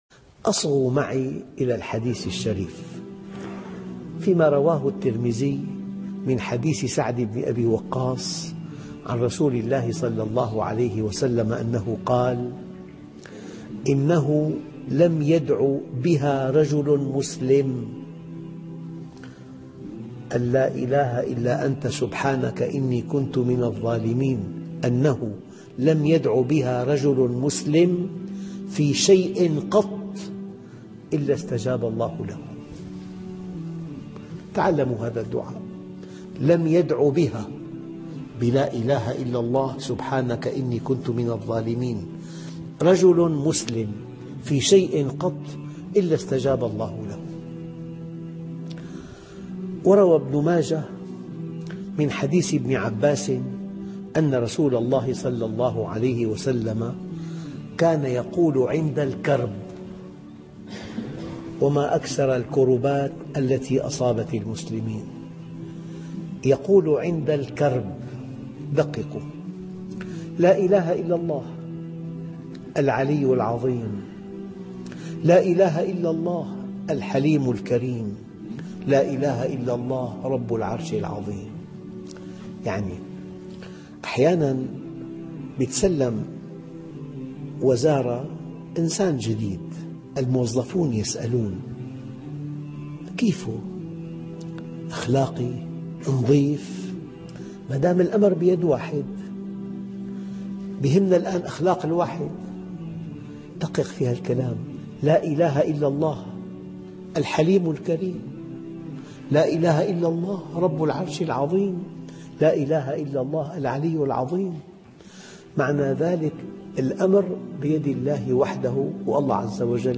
ماذا يجب أن تقول عند الكرب والحزن ؟؟؟ ..... درس هااام ......